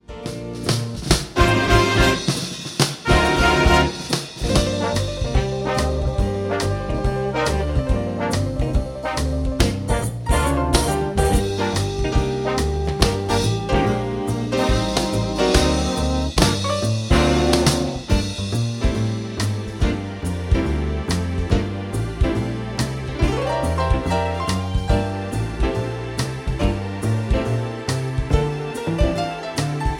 Gm
MPEG 1 Layer 3 (Stereo)
Backing track Karaoke
Pop, Jazz/Big Band, 2000s